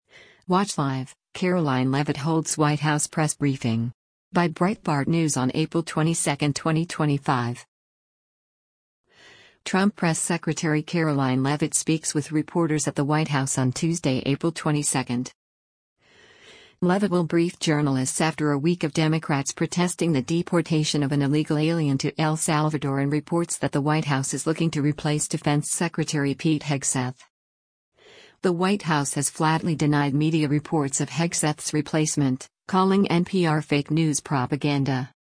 Trump Press Secretary Karoline Leavitt speaks with reporters at the White House on Tuesday, April 22.